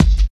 51 KICK 2.wav